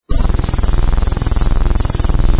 enemy3_flying.wav